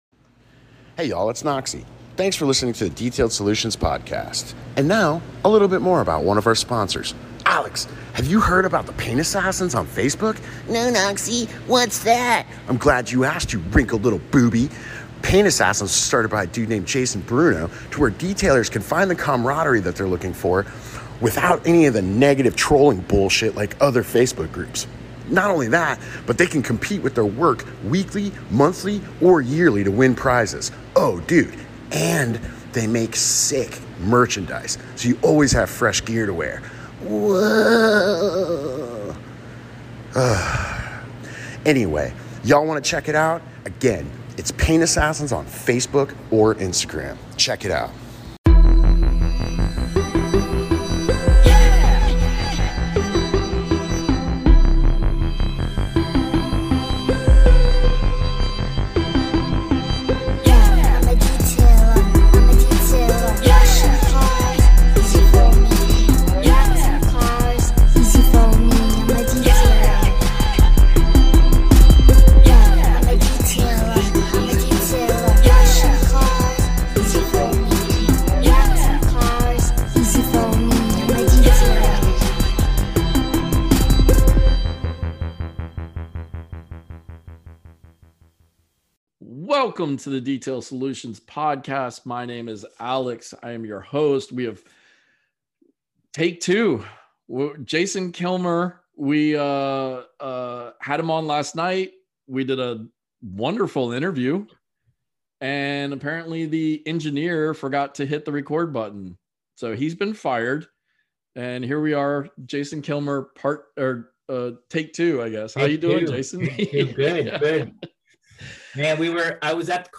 I feel this interview was the better of the two and had a little mistake not been made we wouldn't have this version.